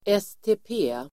Uttal: [este:p'e:]